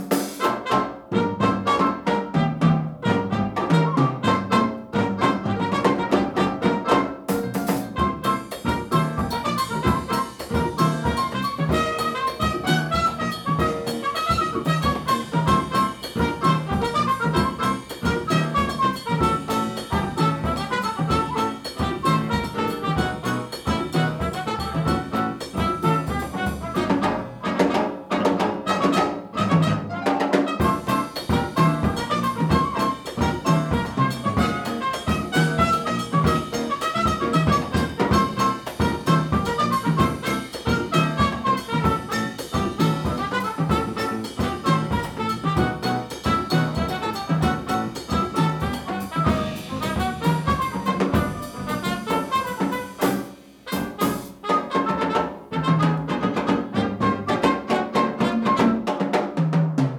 These are tapes I made in bars and clubs and small concert halls when I was a graduate student at Stanford, fifteen inches per second (15 IPS, 38 cm/sec) quarter-inch half-track open-reel tape with a well-placed pair of Nakamichi CM-700 cartioid microphones and a pair of ReVox A77 tape decks.
At "24/96" digital resolution the tape is still clearly better, the higher digital resolutions are not, so I use 24/96.
Here is one minute of the good file from Windows 10 and here is one minute of the bad file I got from Windows 11.